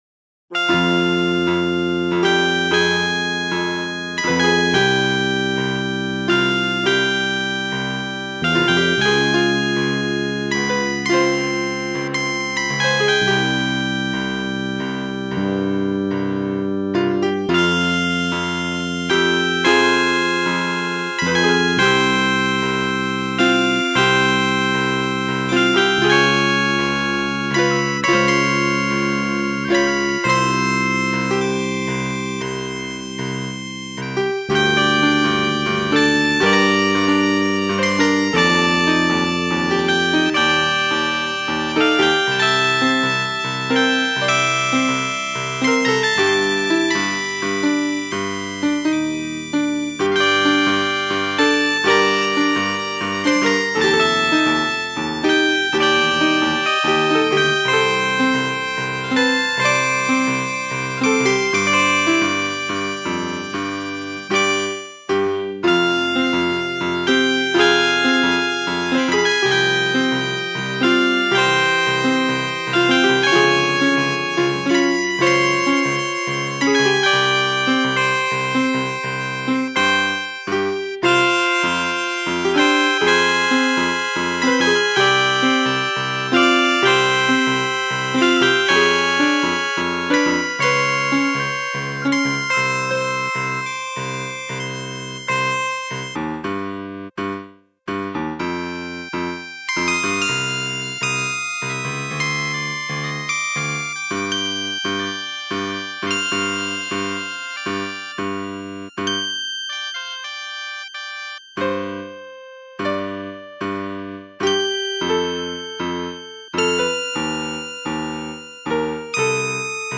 Abrabic Style Tune